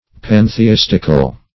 Definition of pantheistical.
pantheistical.mp3